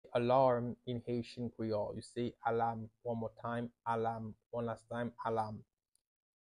“Alarm” means "alam" in Haitian Creole – “Alam” pronunciation by a native Haitian teacher
“Alam” Pronunciation in Haitian Creole by a native Haitian can be heard in the audio here or in the video below:
How-to-say-Alarm-in-Haitian-Creole-–-Alam-pronunciation-by-a-native-Haitian-teacher.mp3